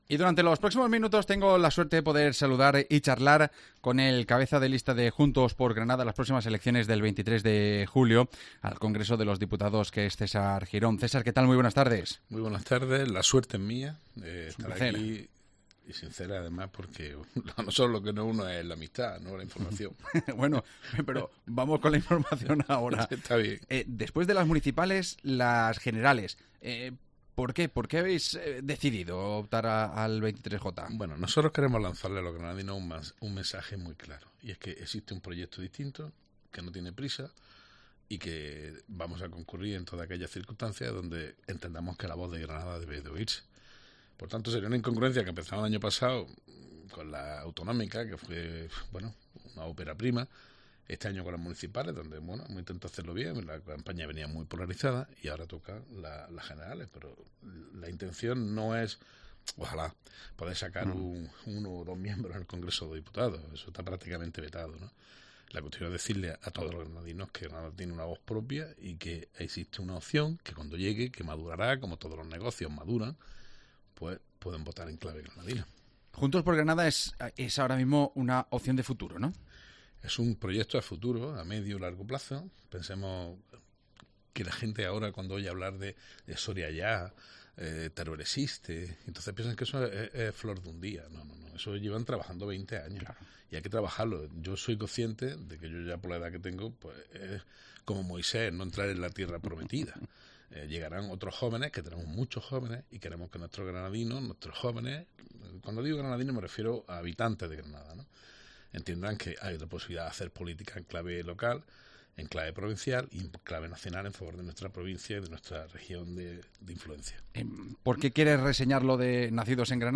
AUDIO: El número uno al Congreso ha pasado por los micrófonos de COPE para analizar la actualidad de cara al 23J